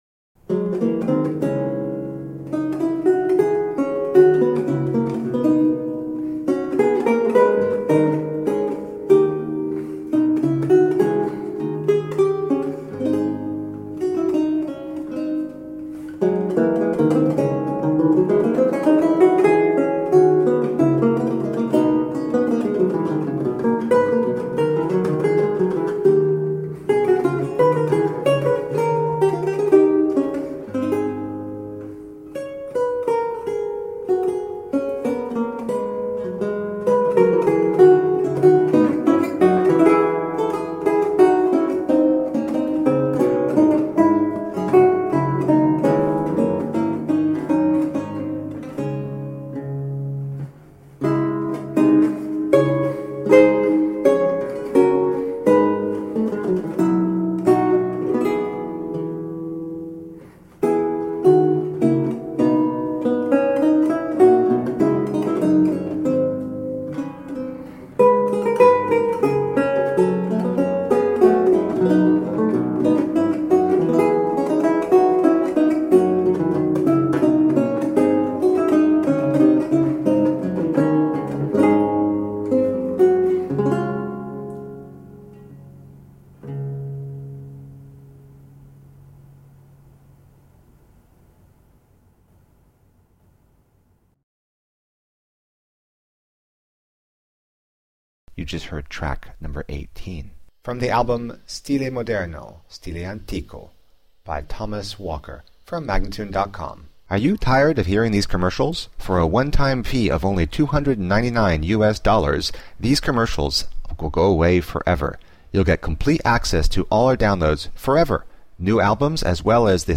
Lute music of 17th century france and italy
Classical, Baroque, Renaissance, Instrumental
Lute